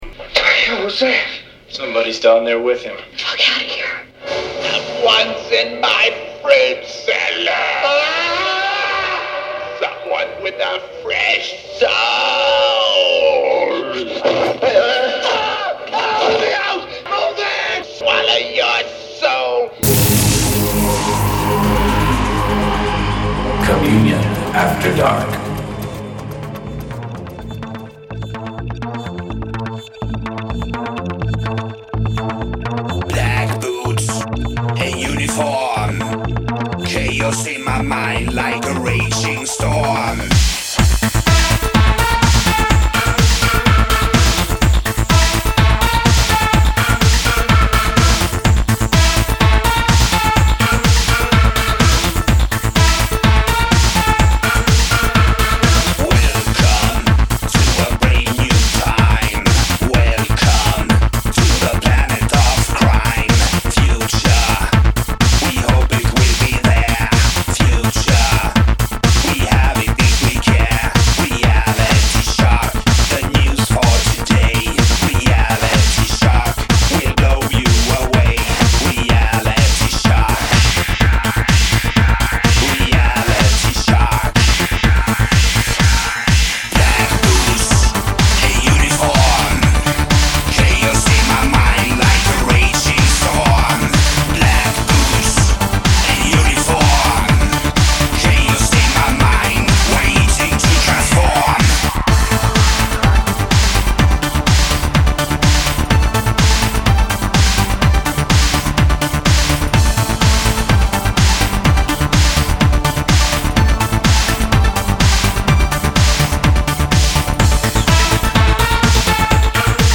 alternative electronic
EBM, dark electro, synth pop, goth, power noise .